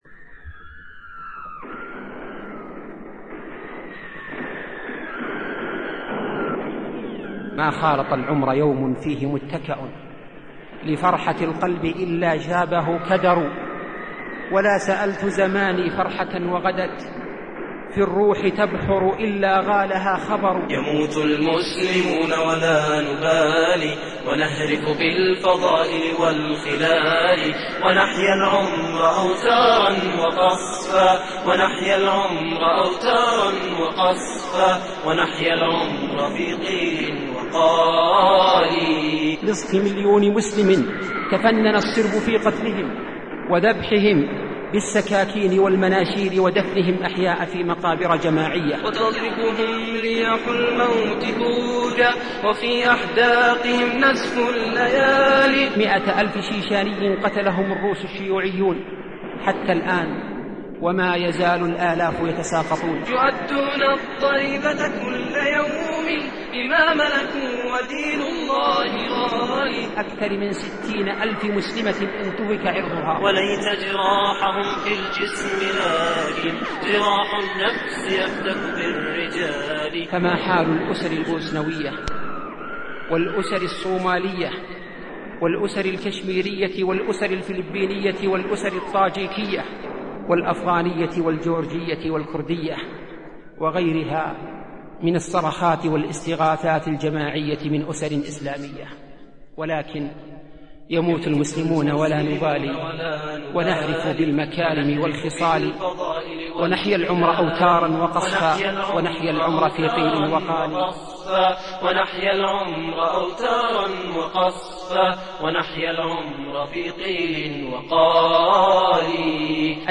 محاضراة